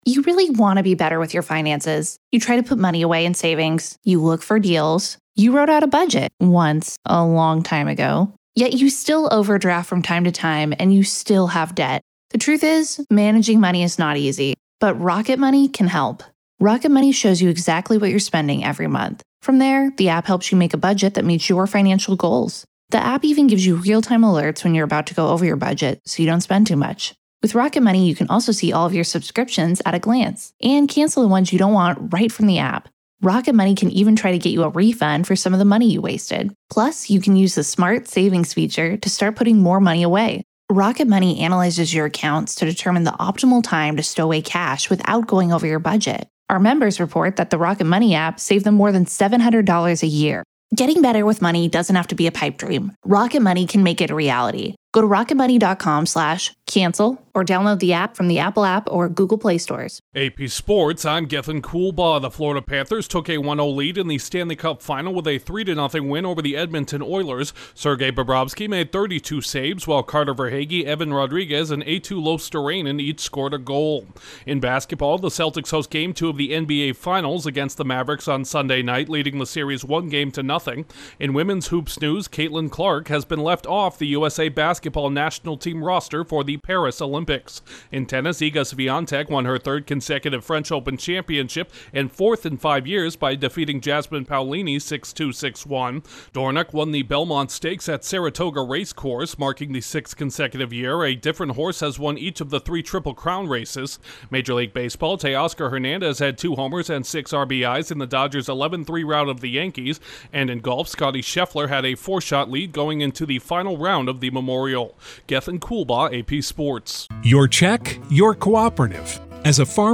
The Panthers strike first in the Stanley Cup Final, Caitlin Clark is snubbed by USA Basketbal, Iga Swiatek claims another French Open crown and Dornoch prevails at the Belmont Stakes. Correspondent